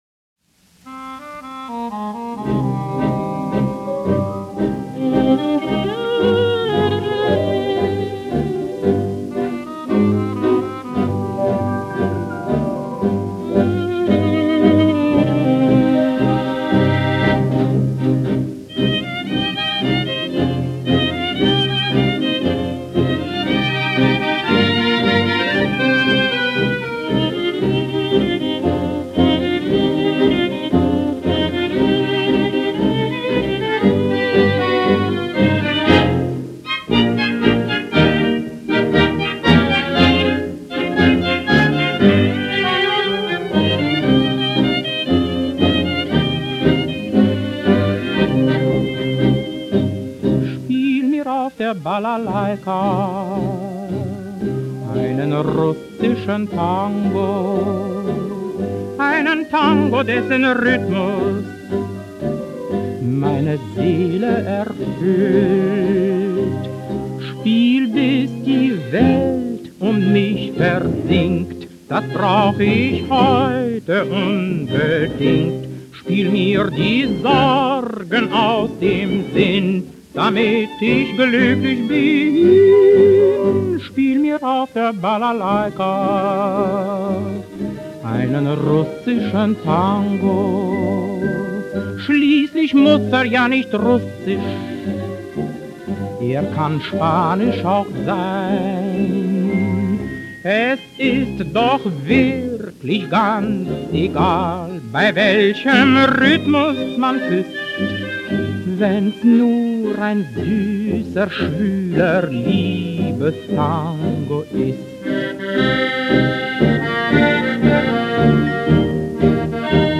Красивое танго!